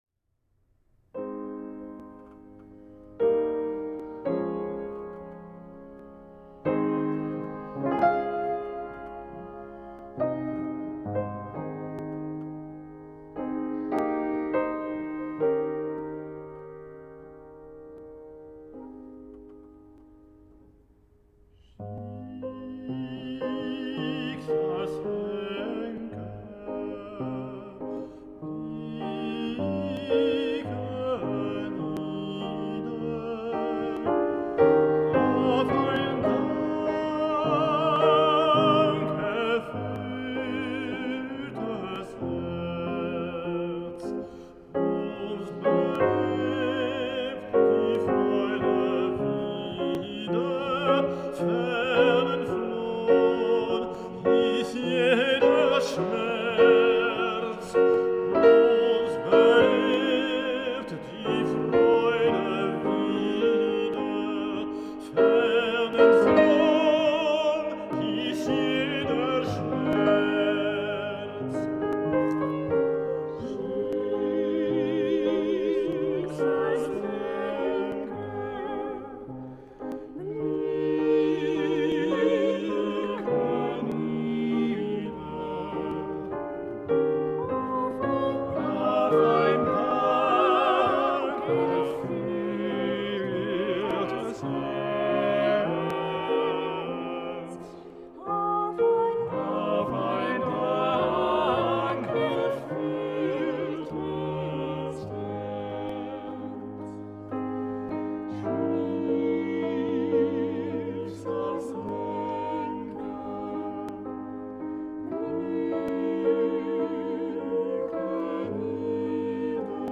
Sur scène, six artistes polyvalents : chanteurs lyriques, instrumentistes (piano, violon, violoncelle) et comédiens, se préparent à vivre une soirée festive - mais ce soir-là Schubert tarde à venir...
soprano
baryton
mezzo-soprano Enregistrement